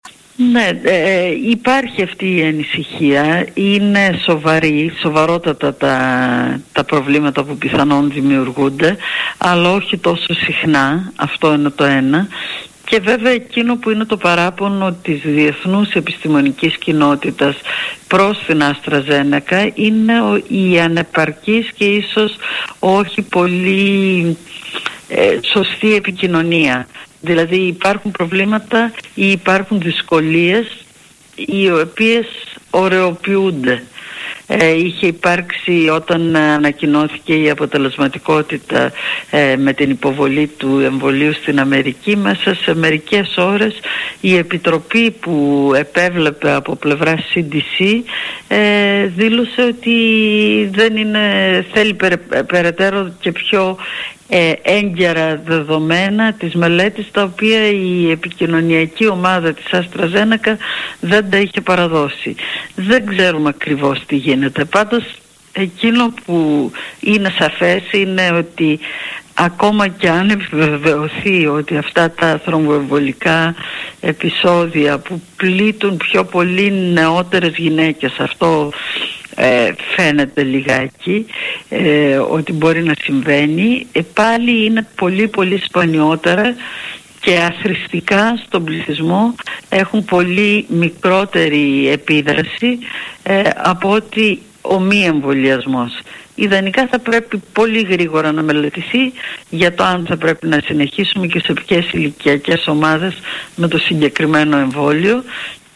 στον Realfm 97,8 και στην εκπομπή του Νίκου Χατζηνικολάου